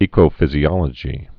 (ēkō-fĭzē-ŏlə-jē, ĕkō-)